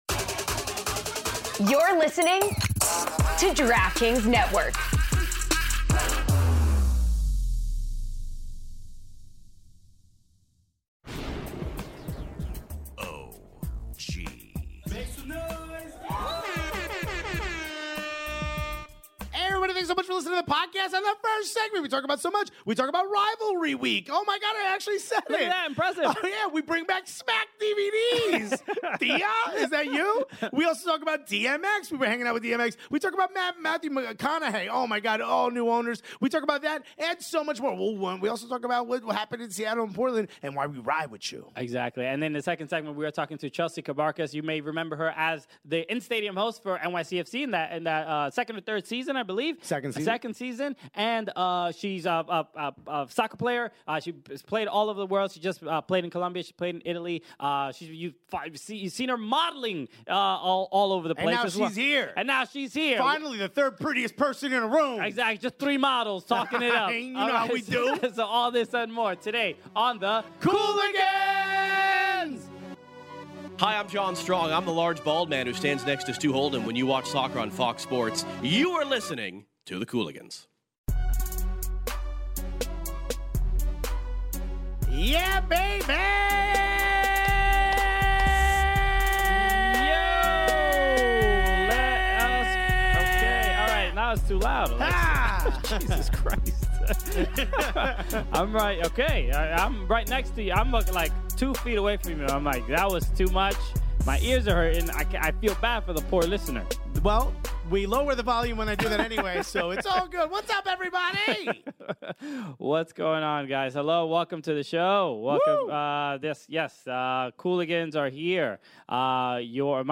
With Jaedyn Shaw returning, the hosts debate who’s in, who’s out, and what’s next for the squad. Plus, a look ahead to a crucial Week 25 in the NWSL - with Gotham vs. Louisville stealing the spotlight.